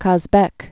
(käz-bĕk), Mount